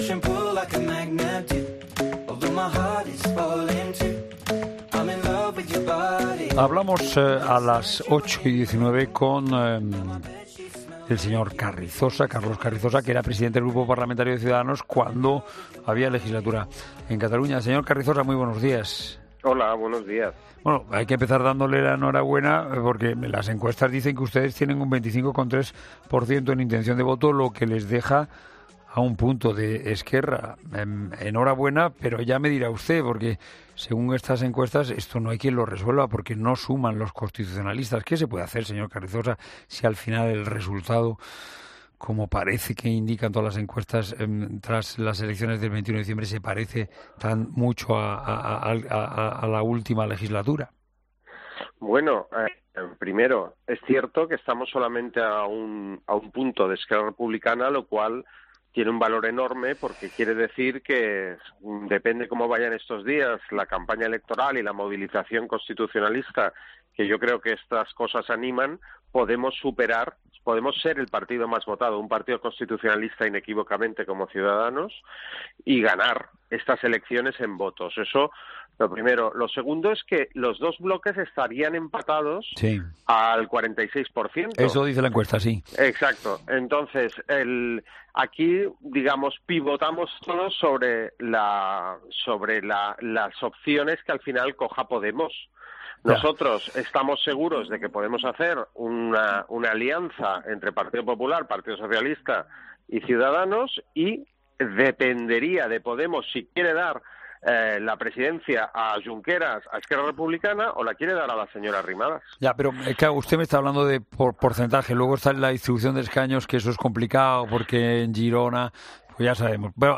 Entrevista a Carlos Carrizosa (Ciudadanos): "Queremos la unión de los constitucionalistas"
Entrevista política